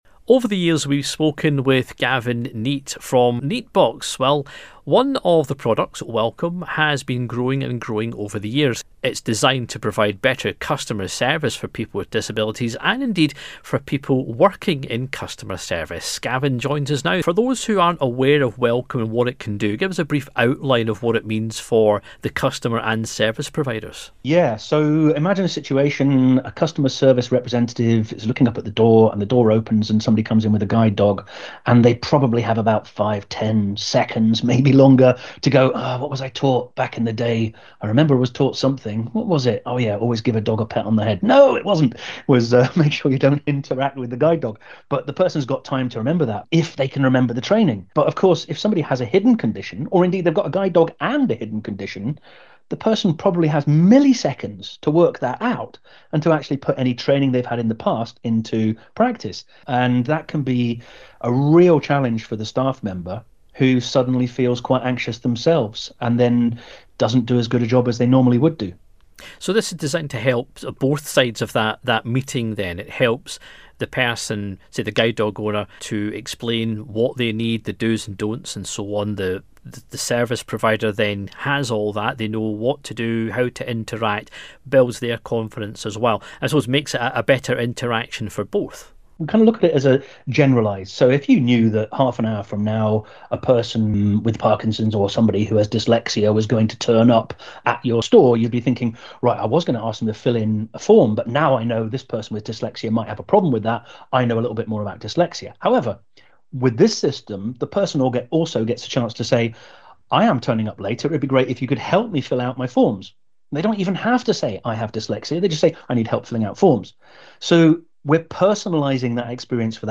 RNIB Connect